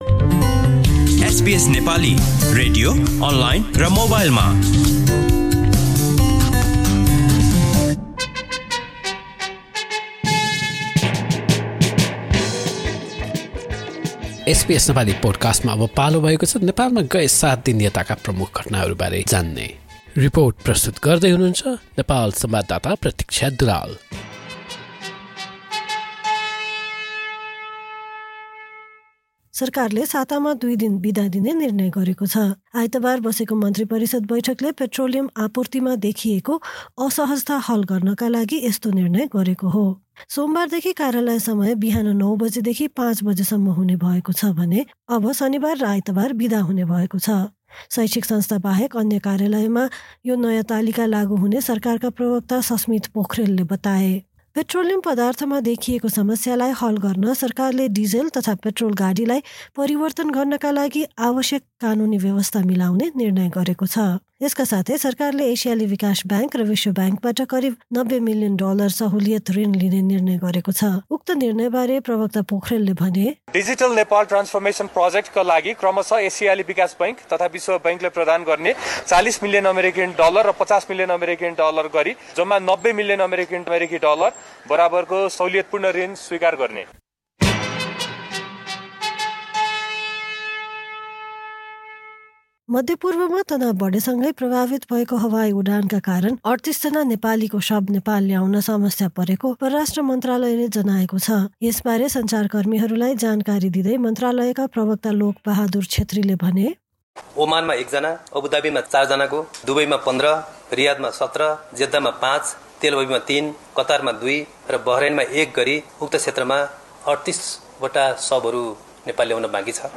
Listen to our weekly report on the major news in Nepal over the past seven days.